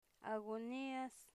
Phonological Representation a'gonias
agonias.mp3